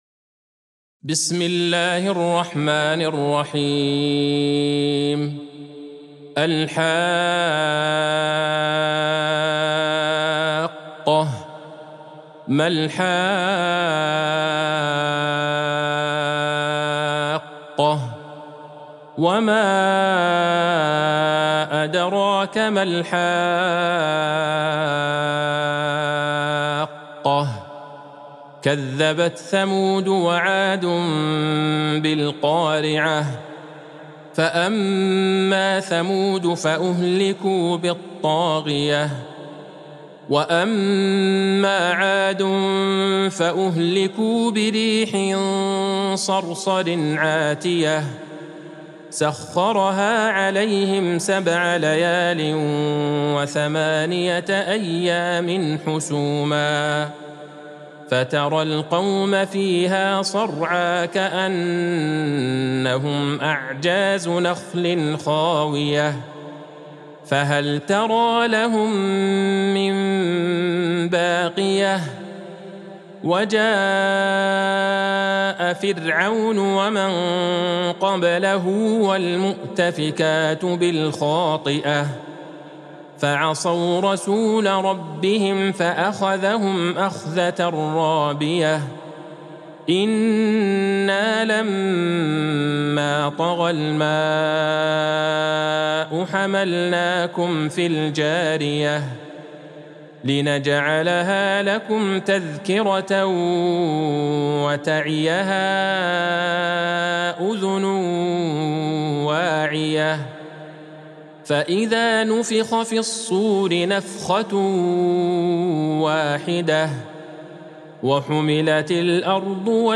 سورة الحاقة Surat Al-Haqqa | مصحف المقارئ القرآنية > الختمة المرتلة ( مصحف المقارئ القرآنية) للشيخ عبدالله البعيجان > المصحف - تلاوات الحرمين